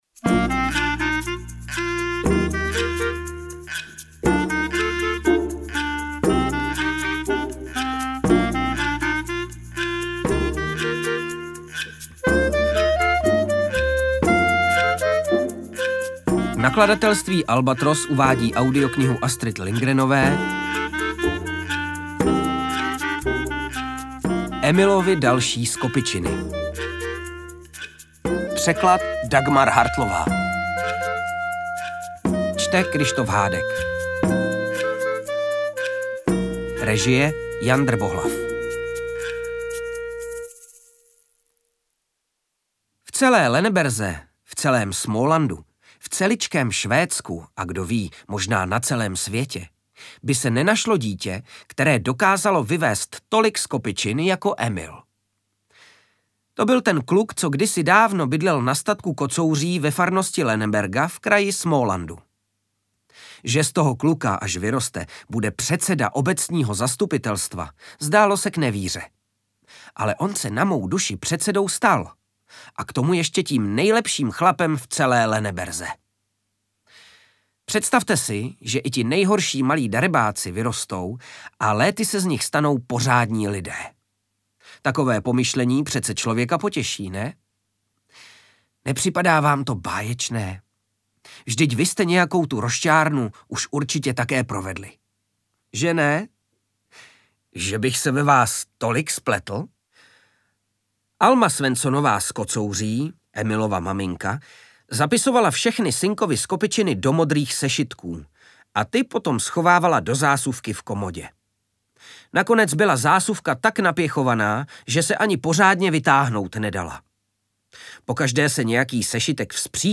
Audiobook
Read: Kryštof Hádek